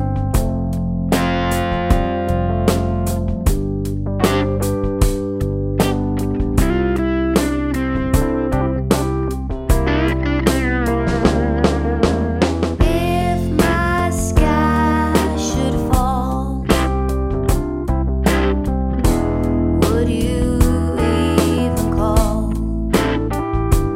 Easy Listening